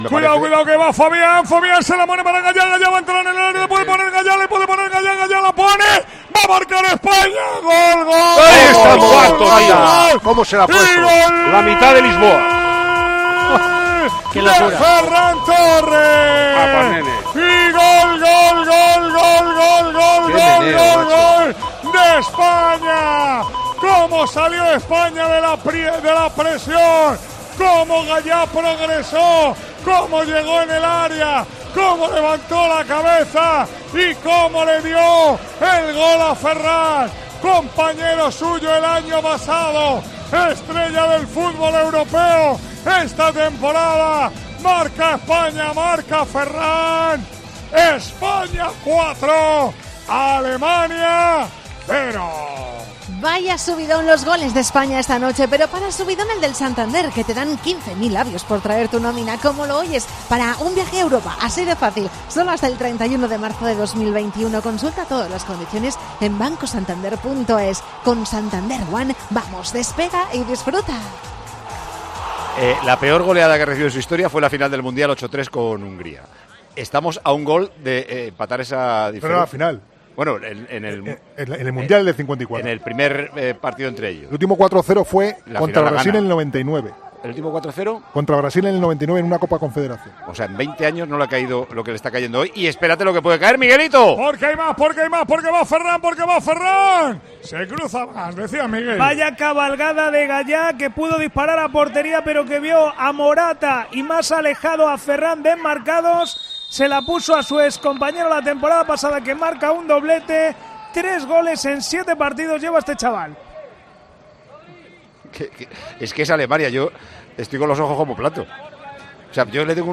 - ESCUCHA LOS SEIS GOLES DEL PARTIDO NARRADOS POR MANOLO LAMA: